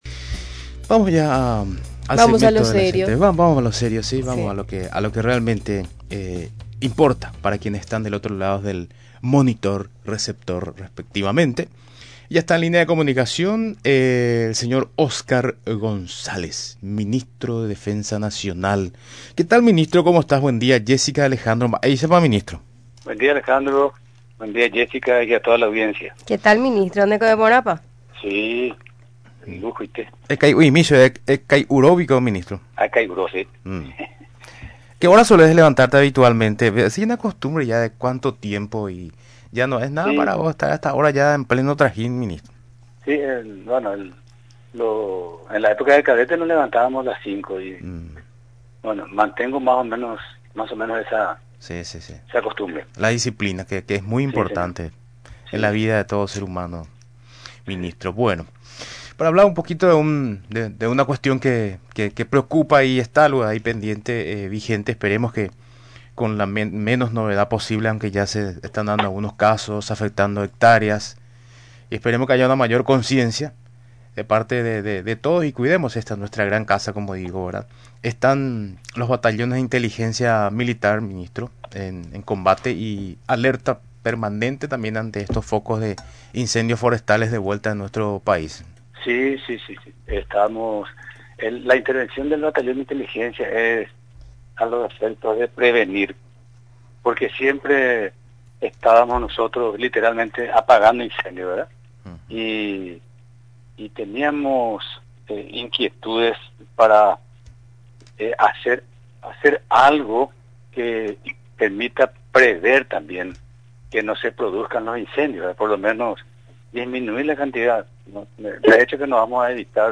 Durante la entrevista en Radio Nacional del Paraguay, el secretario de Estado, explicó los detalles de los trabajos en que se centra los integrantes de Batallones de Inteligencia Militar en sofocar el fuego.